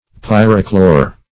Search Result for " pyrochlore" : The Collaborative International Dictionary of English v.0.48: Pyrochlore \Pyr"o*chlore\, n. [Pyro- + Gr.
pyrochlore.mp3